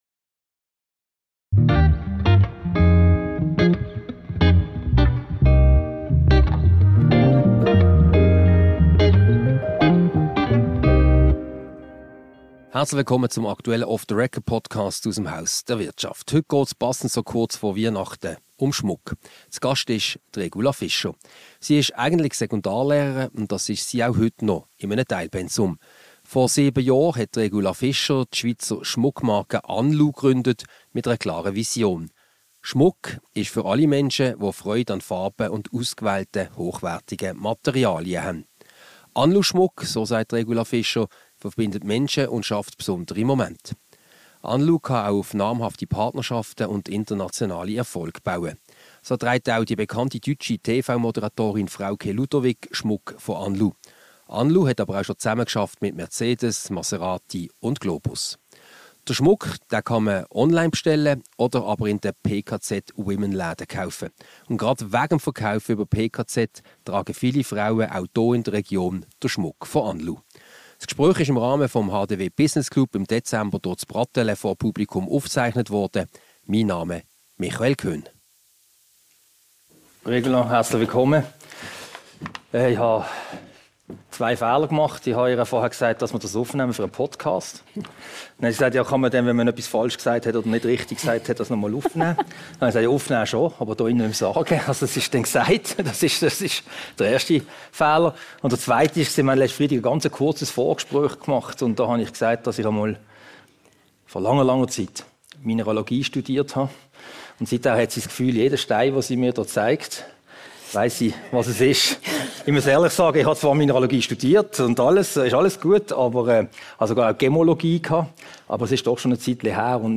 Aufgezeichnet am HDW Business Club Lunch vom 7. Dezember 2023.